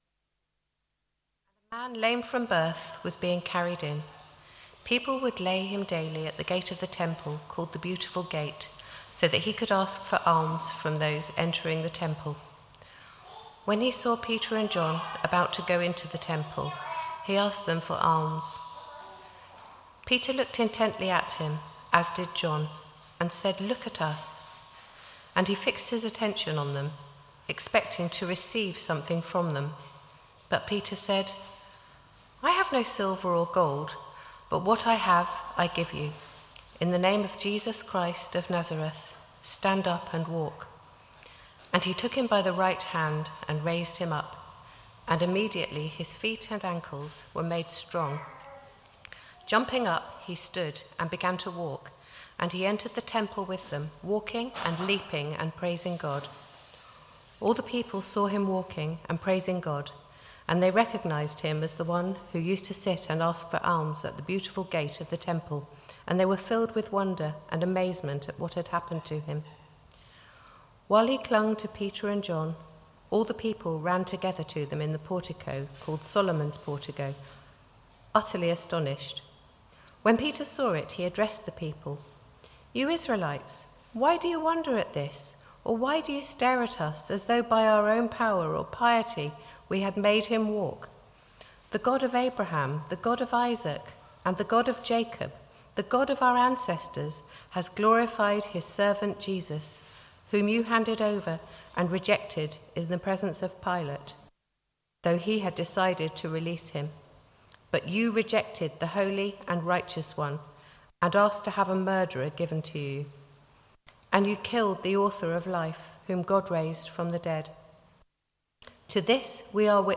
Sermon 30th June 2024 11am gathering
We have recorded our talk in case you missed it or want to listen again.